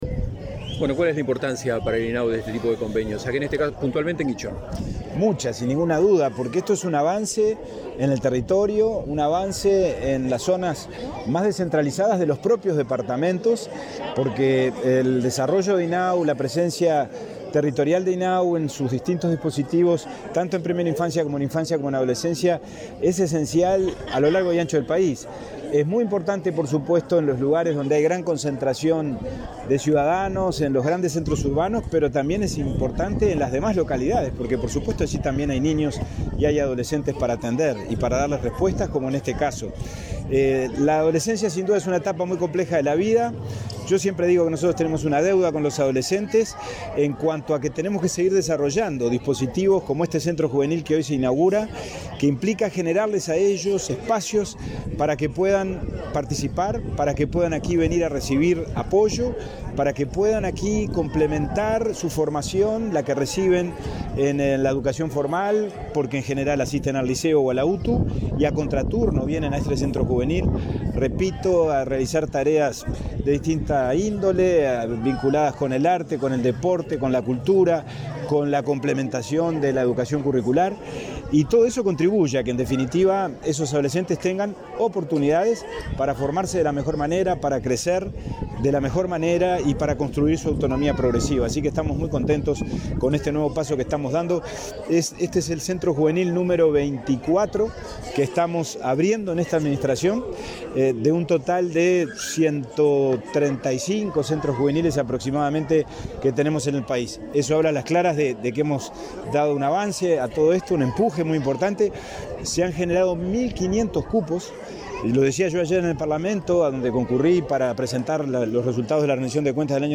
Declaraciones del presidente del INAU, Pablo Abdala
El presidente del Instituto del Niño y el Adolescente del Uruguay (INAU), Pablo Abdala, dialogó con la prensa en Paysandú, donde inauguró un centro